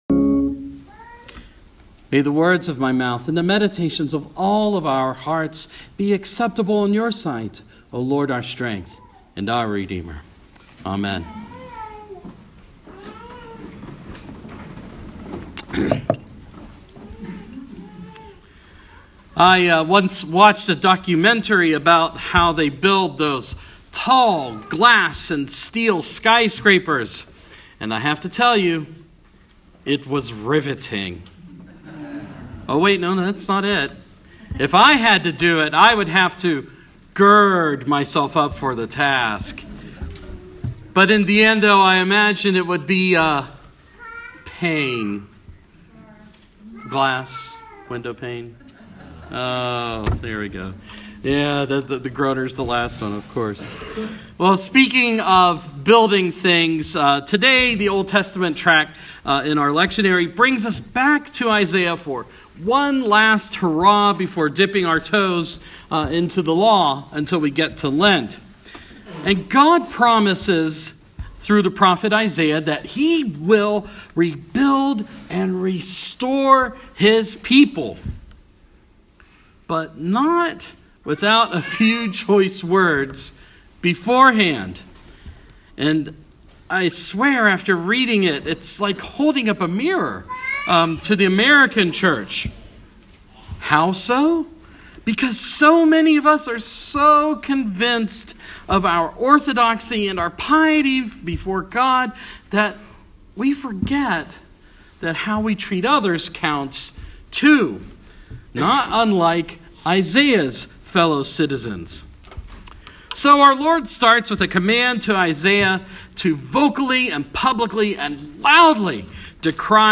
Sermon – 5th Sunday in Epiphany